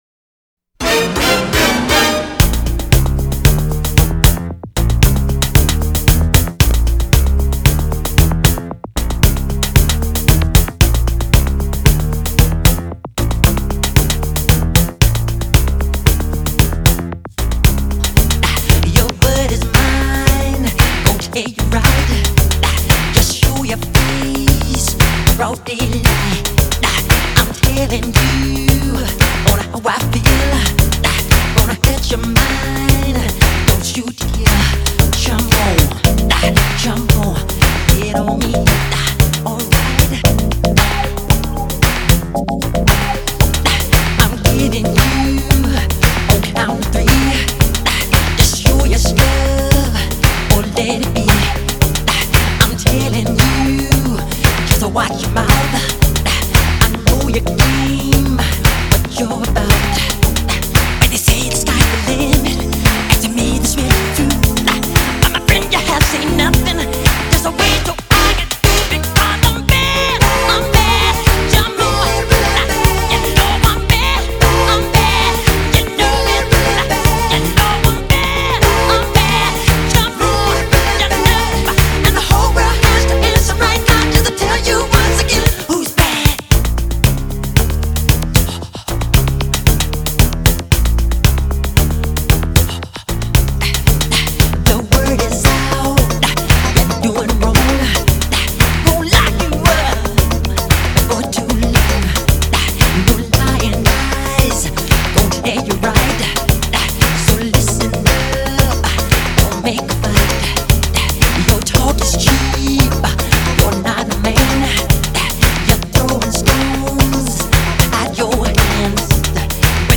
Жанр: Pop-Rock, Soul Pop, downtempo, Funk